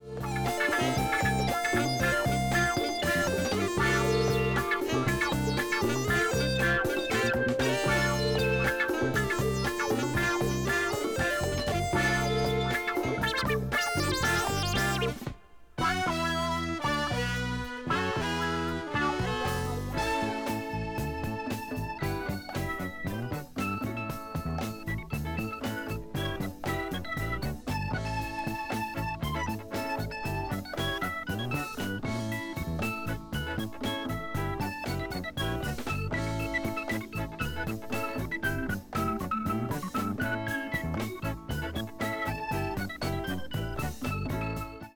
Funk / Soul